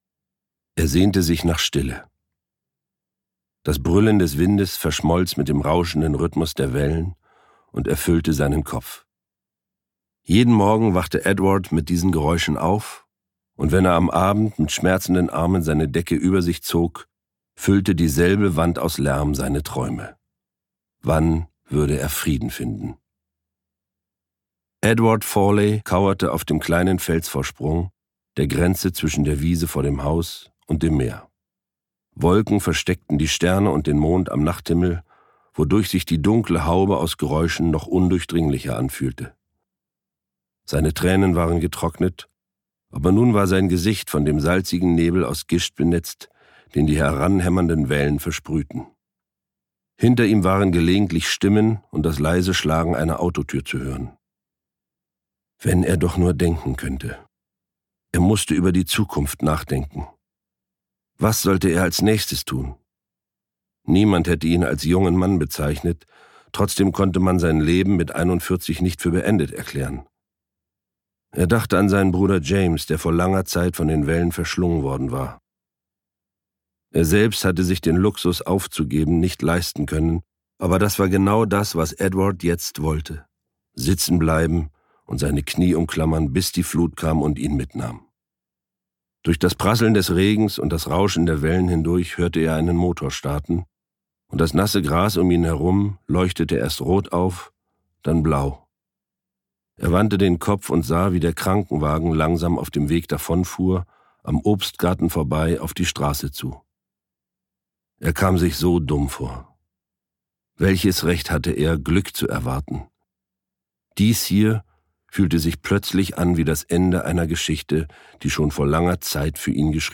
Eine irische Familiengeschichte Graham Norton (Autor) Charly Hübner (Sprecher) Audio Disc 2020 | 2.